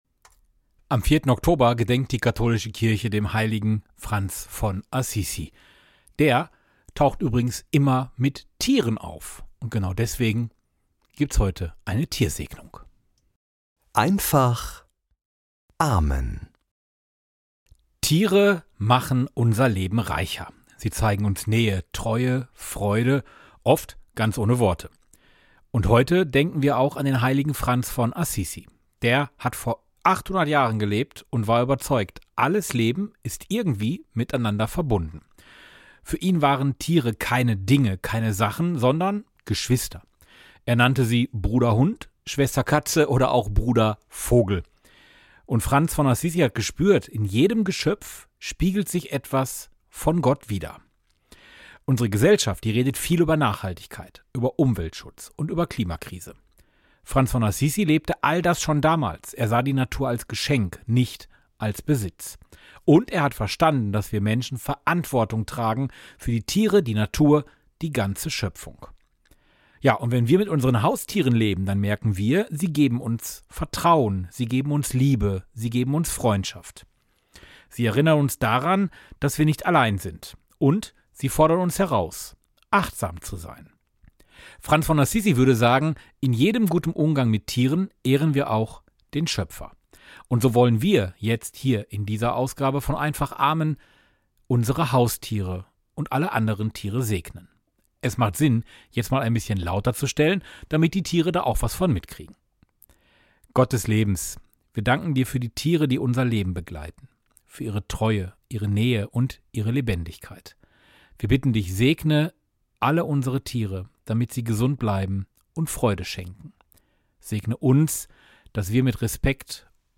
Der Podcast mit Gebeten, Impulsen und Gedanken - in einfacher Sprache.